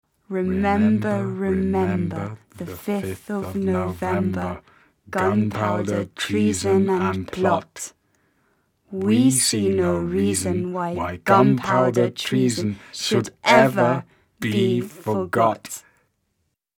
Below, is a traditional English nursery rhyme written on an e-card (click on the rhyme if you want to hear it).